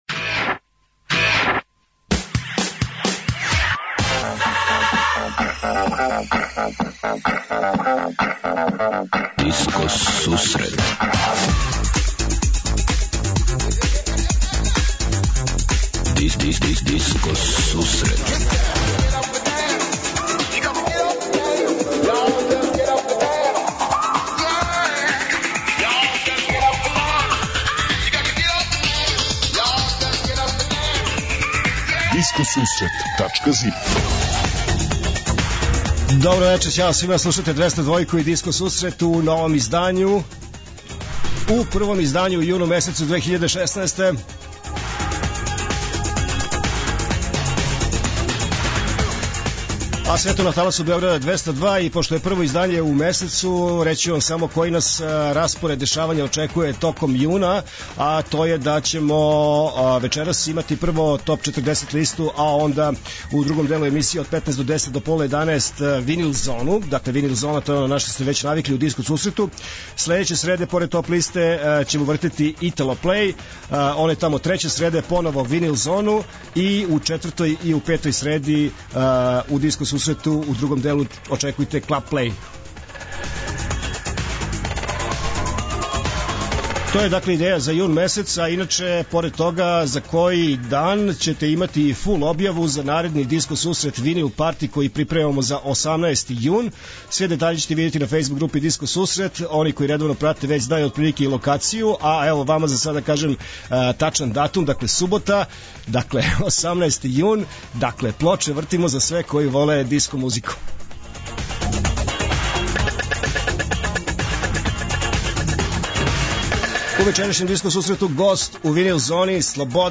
Од 20:30 Диско Сусрет Топ 40 - Топ листа 40 највећих светских диско хитова.
Од 21:45 Винил Зона - Слушаоци, пријатељи и уредници Диско Сусрета за вас пуштају музику са грамофонских плоча.